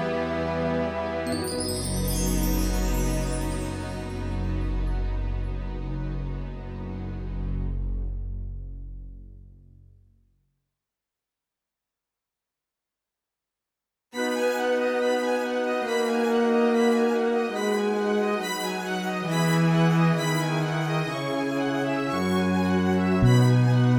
Musicals